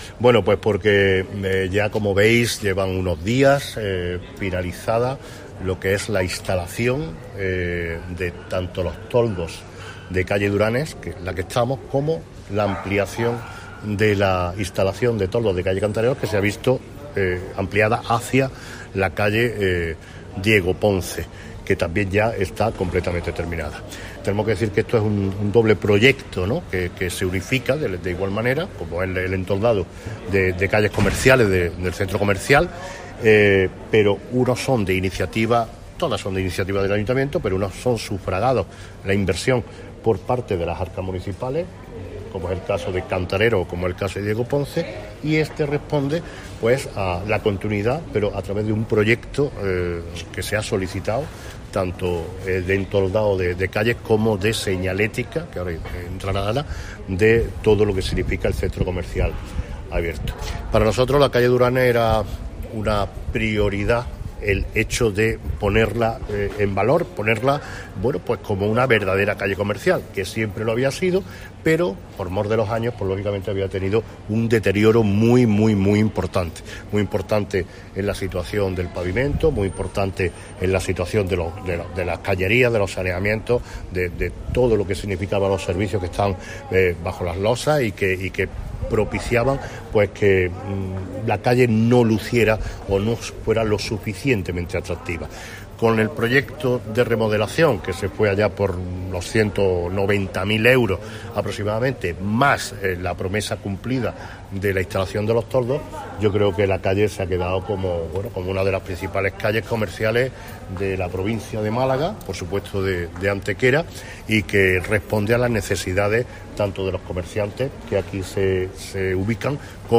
El alcalde de Antequera, Manolo Barón, y la teniente de alcalde delegada de Comercio, Ana Cebrián, han informado hoy en rueda de prensa de la reciente conclusión de la instalación de toldos en la calle Diego Ponce y en la remodelada calle Duranes, habiéndose entoldado un total de 95 y 120 metros lineales respectivamente.
Cortes de voz